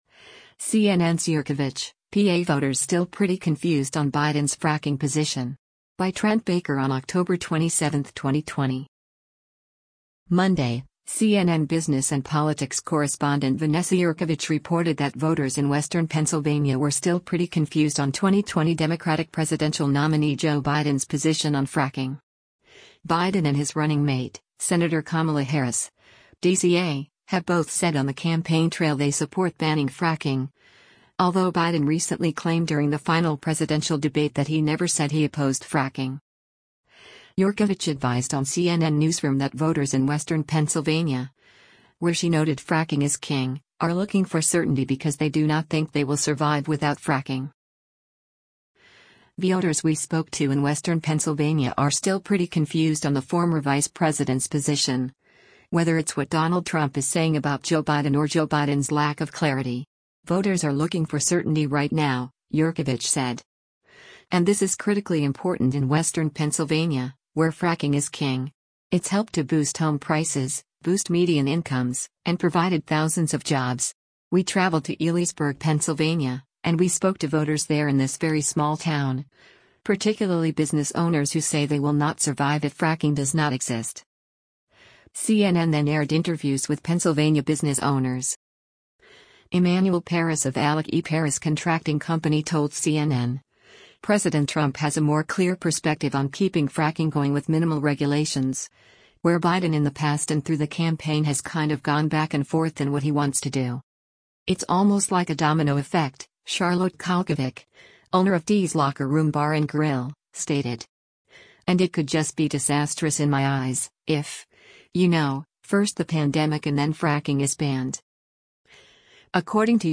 CNN then aired interviews with Pennsylvania business owners.